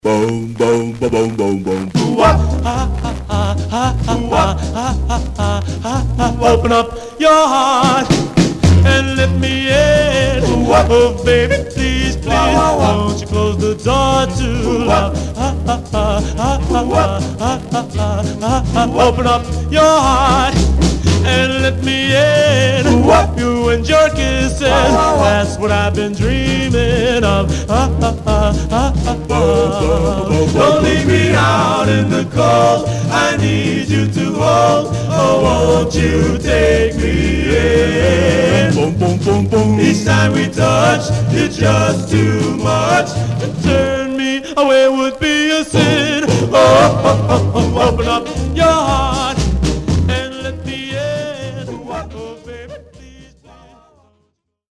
Barely played, Some surface noise/wear Stereo/mono Mono
Male Black Groups